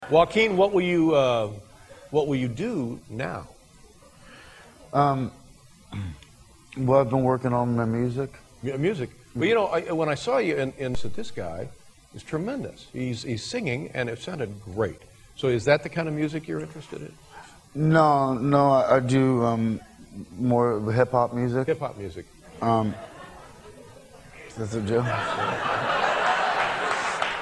Tags: Joaquin Phoenix Joaquin Phoenix on Drugs Joaquin Phoenix on David Letterman Joaquin Phoenix interview funny clip